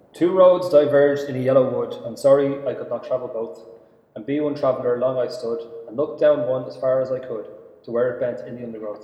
VoiceOver.wav